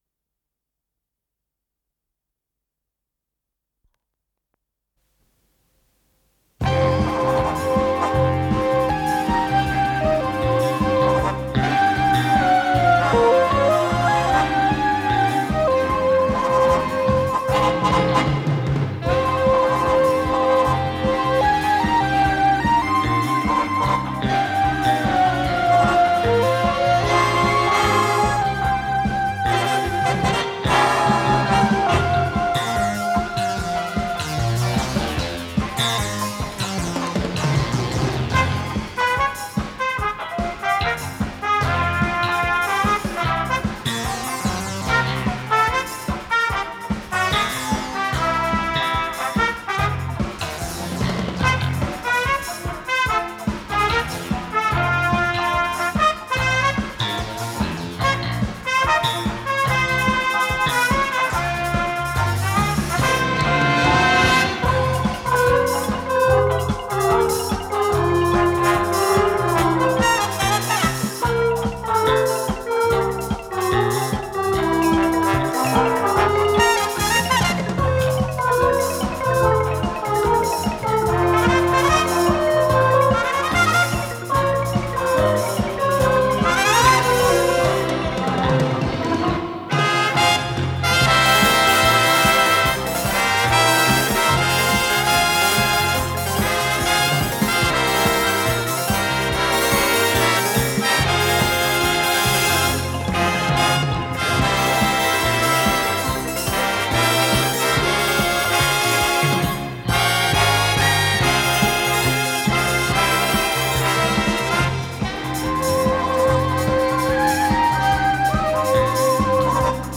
с профессиональной магнитной ленты
Вокализ
ВариантДубль моно